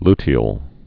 lu·te·al
(ltē-əl)